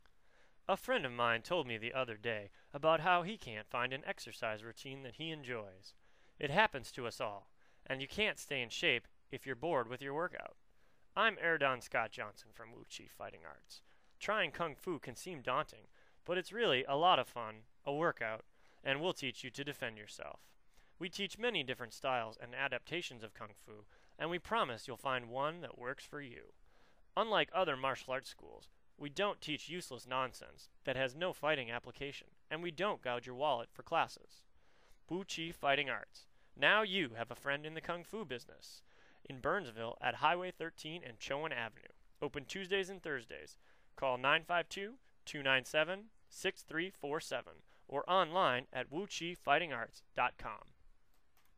Wu Chi Fighting Arts Radio Commercial
WuChiFightingArtsRadioAdvertisement.mp3